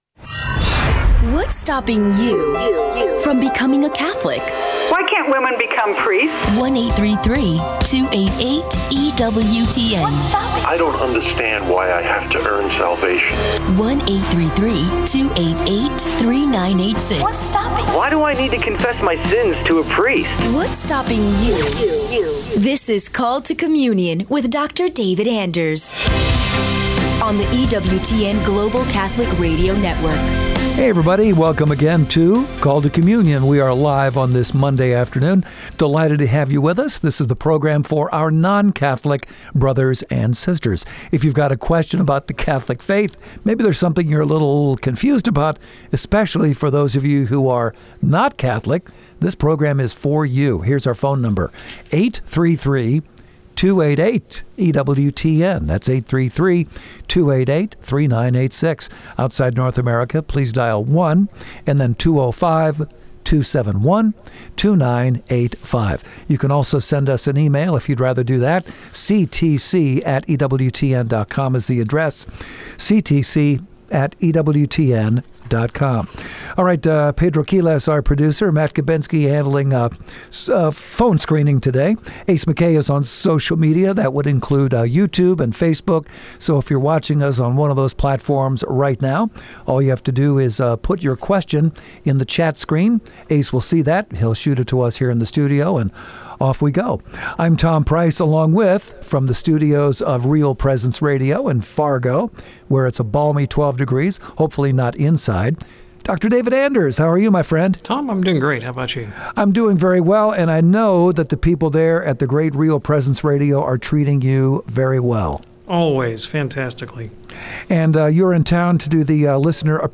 from the Real Presence Radio Studios in Fargo!